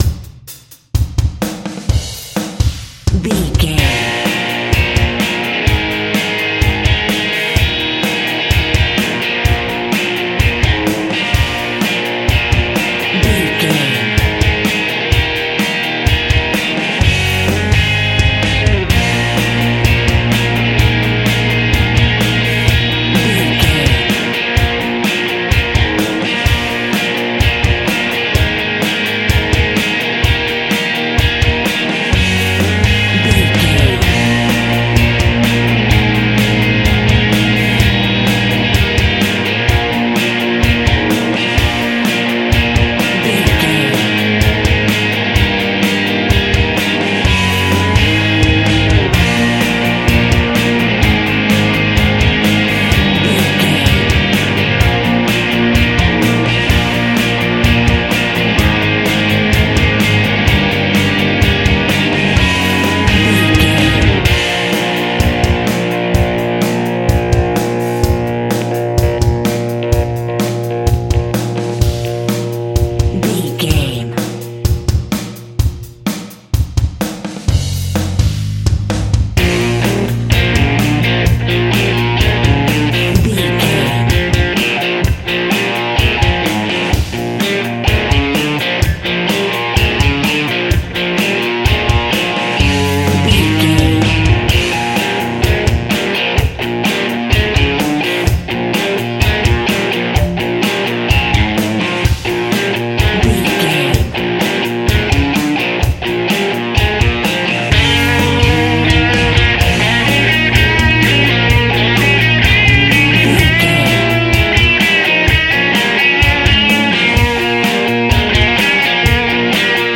Epic / Action
Fast paced
Ionian/Major
B♭
heavy metal
heavy rock
blues rock
distortion
hard rock
Instrumental rock
drums
bass guitar
electric guitar
piano
hammond organ